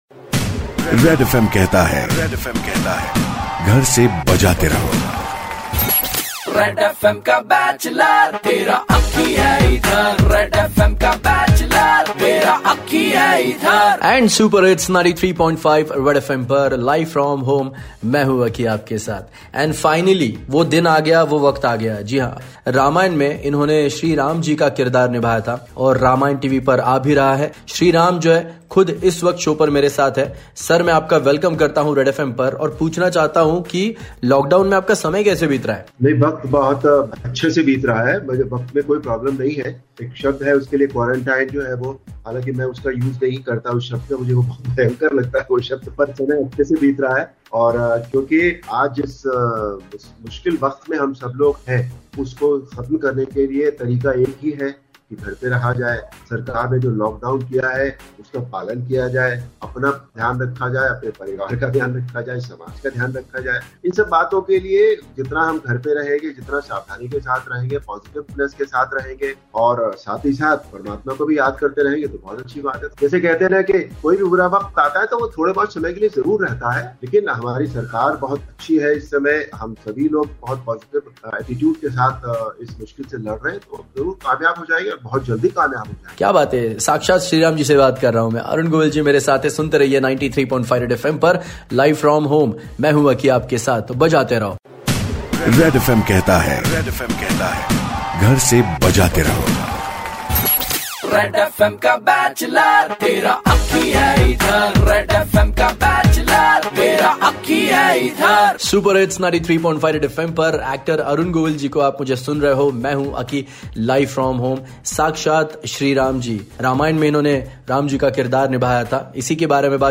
So we talked about Serial, Shoot Time, Old memories, Lockdown with Actor Arun Govil...